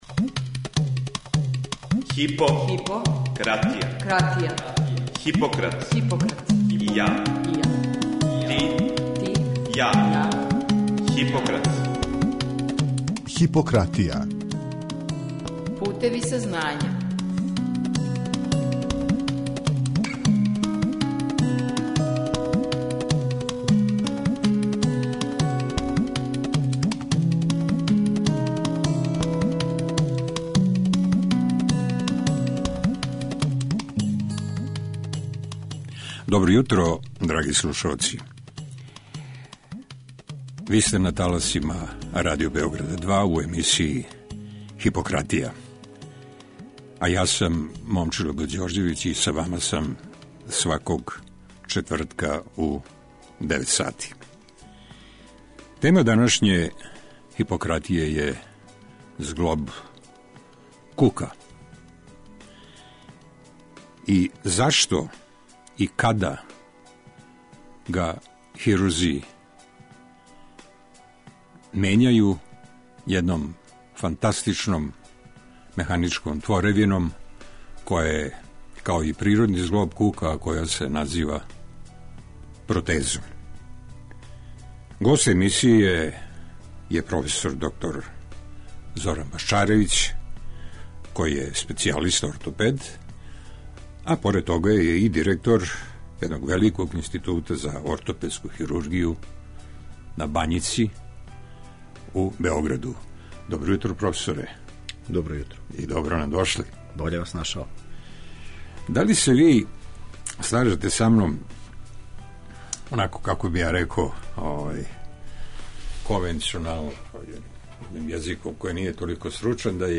О протезама кука и њиховој хируршкој уградњи говориће нам наш драги и уважени гост.